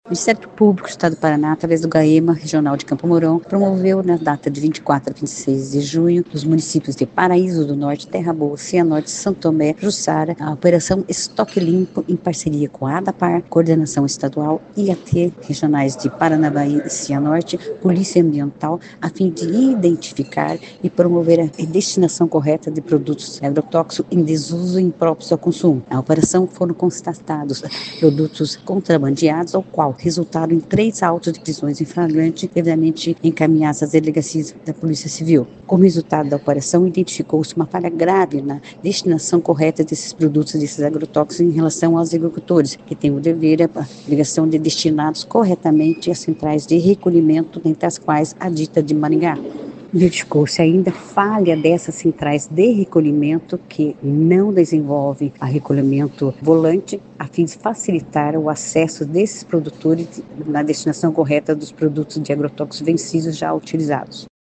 Ouça o que diz a promotora de Justiça Rosana Araújo de Sá Ribeiro.